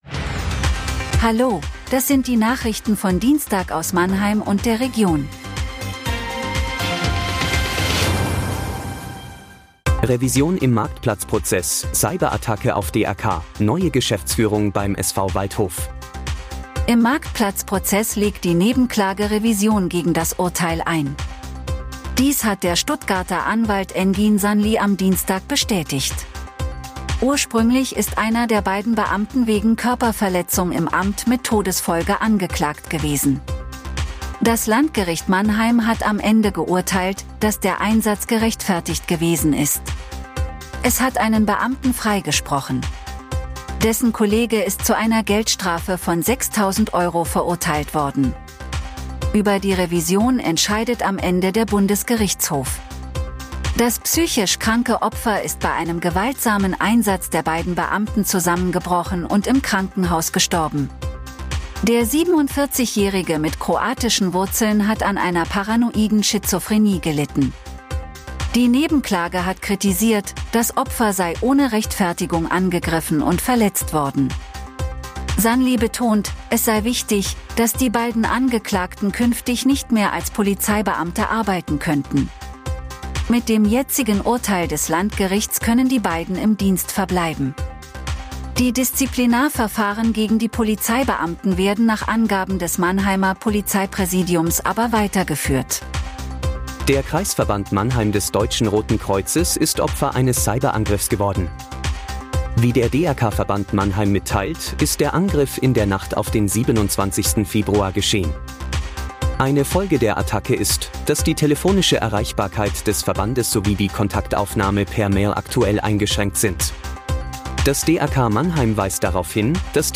Der Nachrichten-Podcast des MANNHEIMER MORGEN
Nachrichten